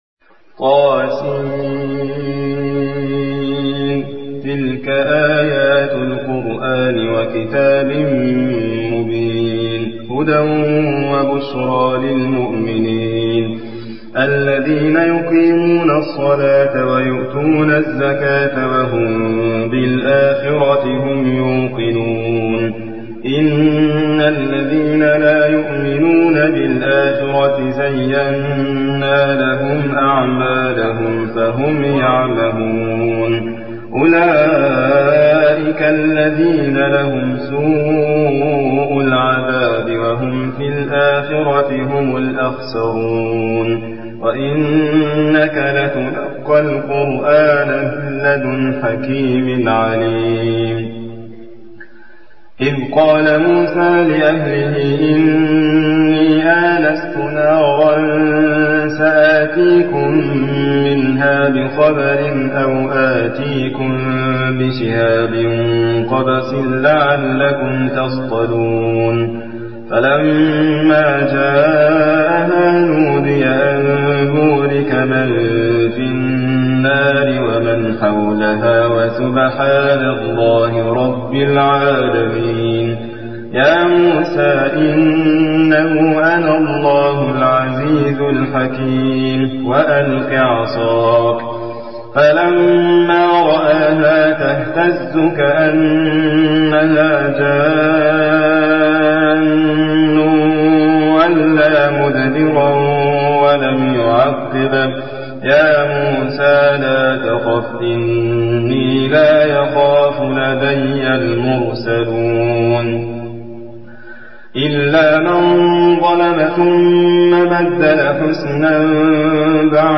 تحميل : 27. سورة النمل / القارئ حاتم فريد الواعر / القرآن الكريم / موقع يا حسين